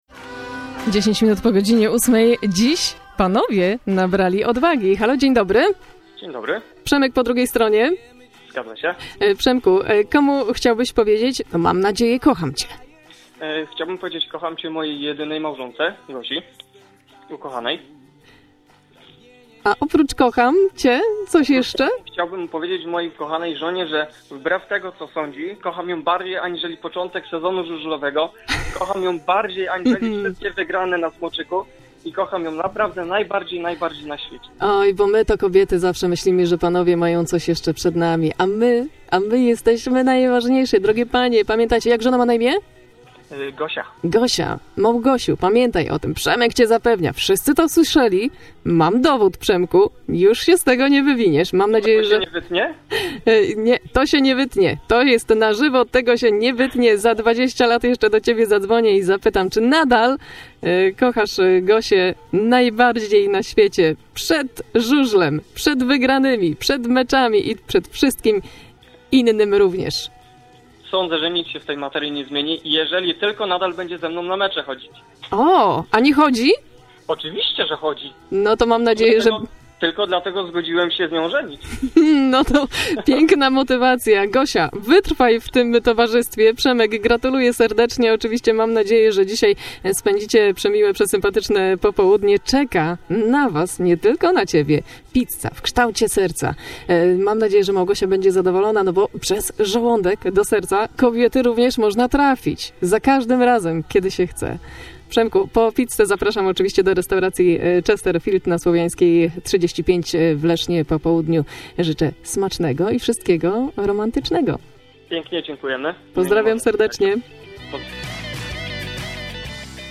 14.02.2014 Nietypowego wyznania miłosnego mogliśmy wysłuchać w walentynkowy piątek na antenie Radia Elka. Słuchacz, który zadzwonił do radia, zapewniał swoją żonę o dozgonnej miłości, jednak postawił jeden warunek: jego kobieta musi nadal towarzyszyć mu na stadionie Smoczyka,  podczas meczów Fogo Unii Leszno. Posłuchaj tego fragmentu audycji w Radiu Elka: